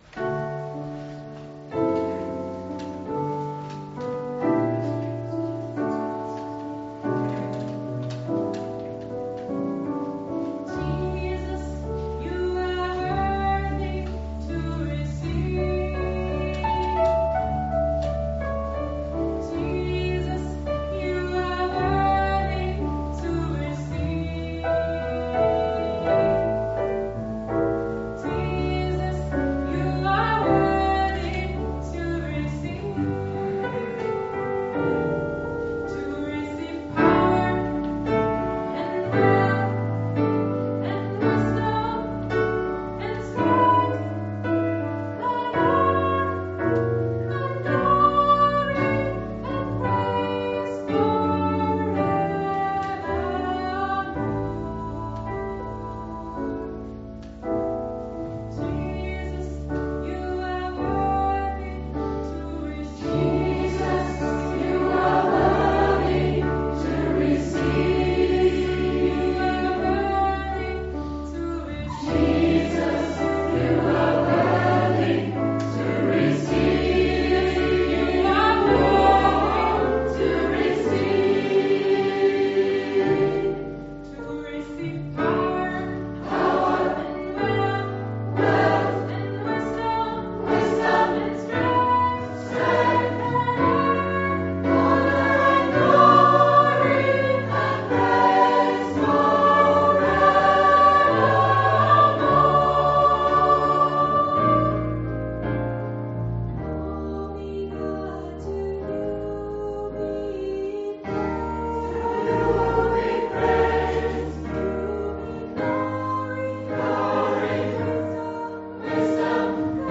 Gospelchor der Gnadenkirche Wien
Jesus You are worthy - Aufnahme aus der Gnadenkirche, Juni 2016 (2,22 mb)